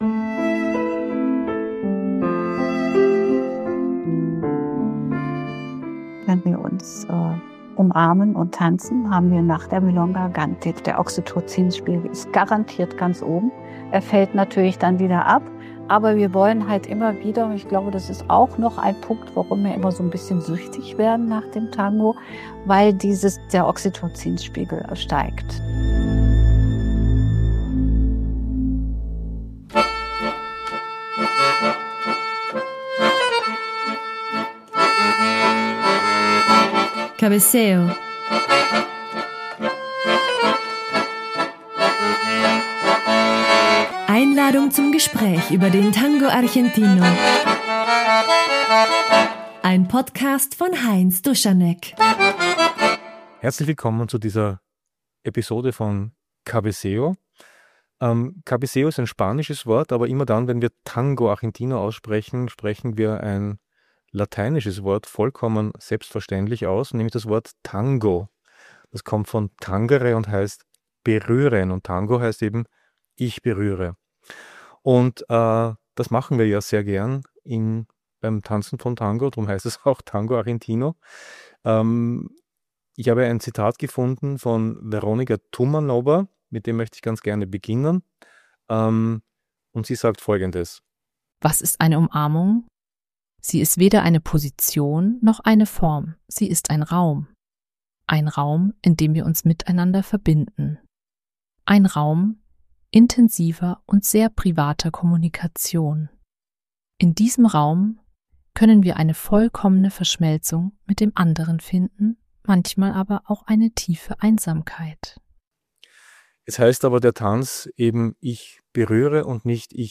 ~ Cabeceo - Gespräche über den Tango Argentino Podcast